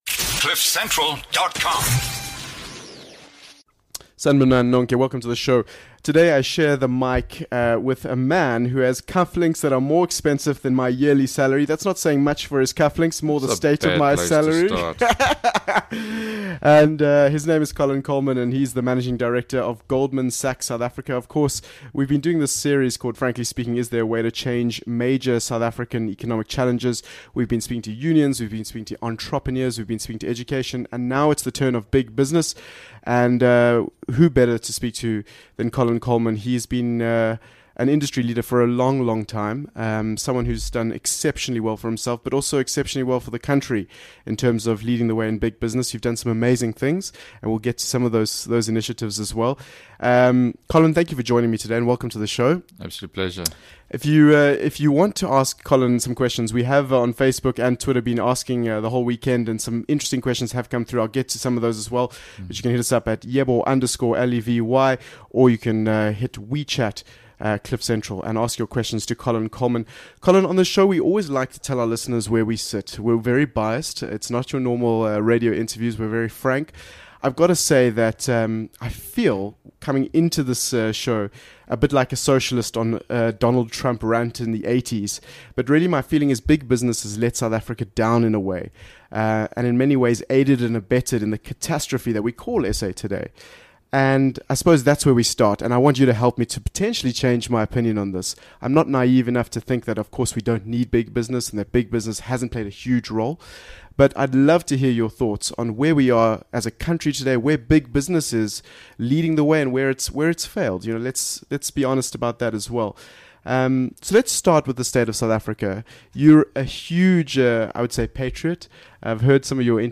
A fascinating conversation with one of our biggest captains of South Africans industry.